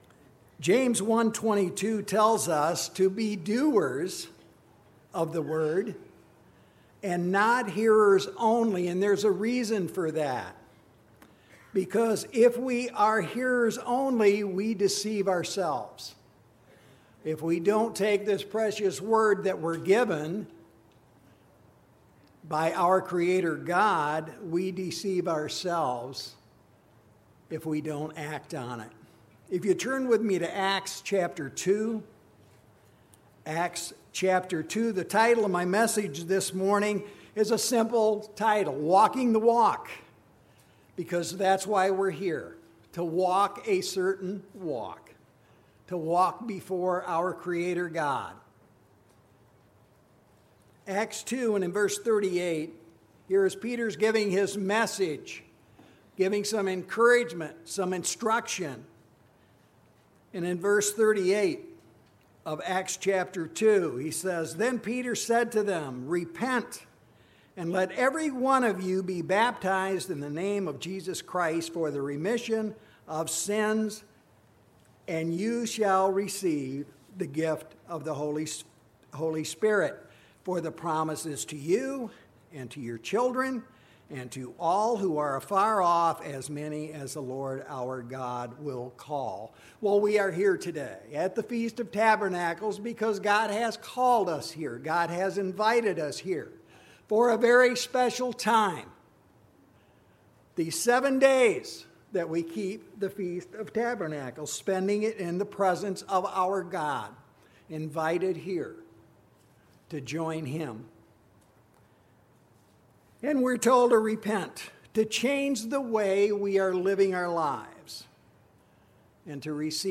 This sermon was given at the Lake George, New York 2018 Feast site.